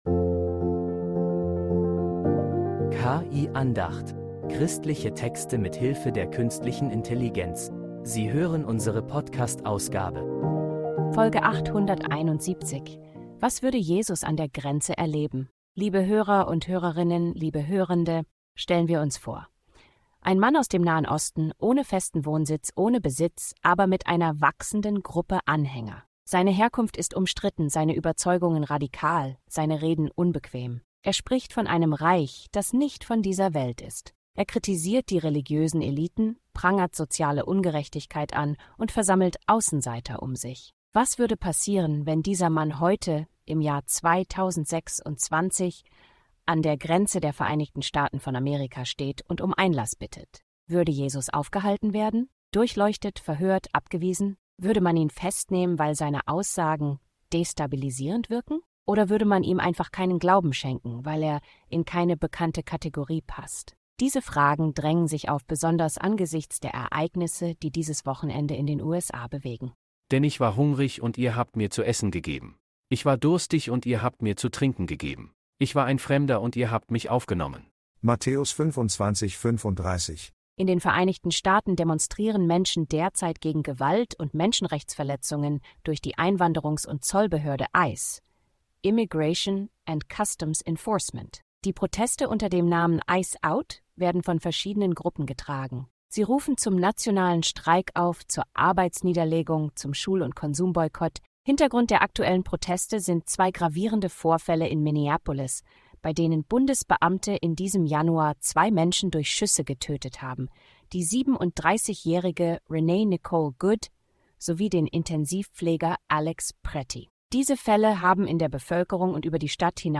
Eine Andacht über Protest, Gerechtigkeit und gelebten Glauben.